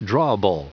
Prononciation du mot drawable en anglais (fichier audio)
Prononciation du mot : drawable